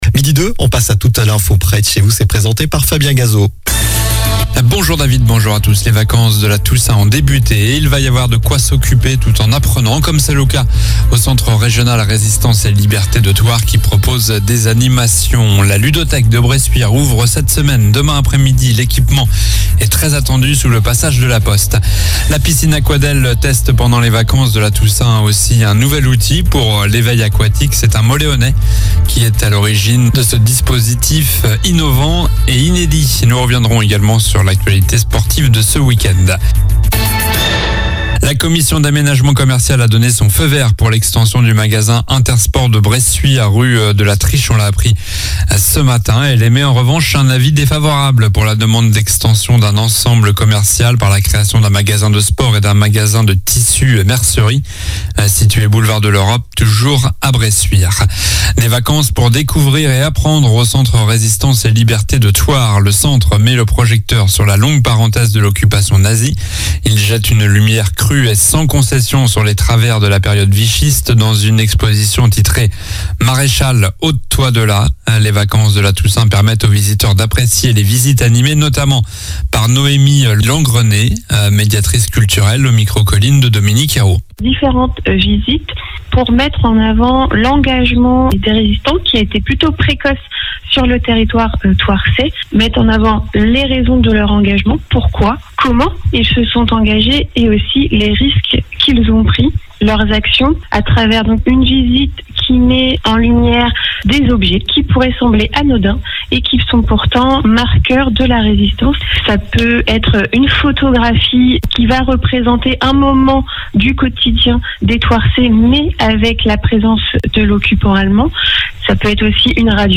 Journal du lundi 23 OCTOBRE (midi)